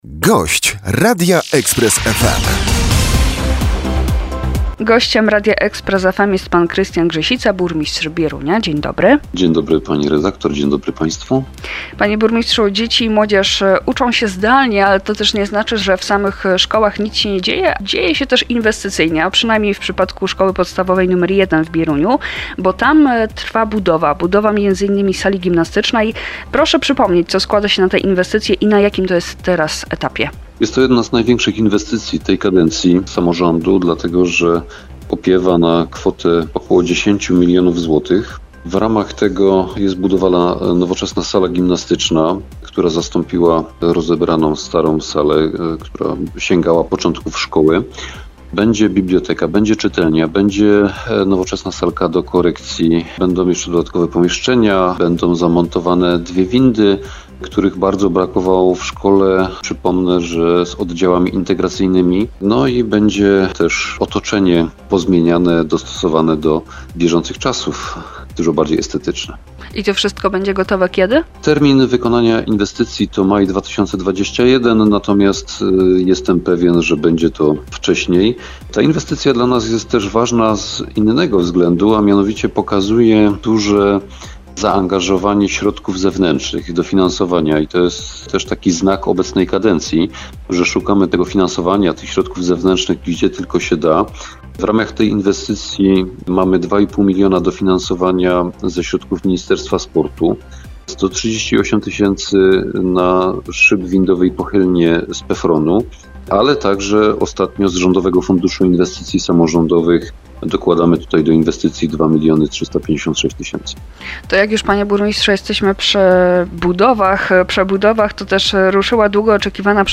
grzesica_gosc_podklad_2711_ns.mp3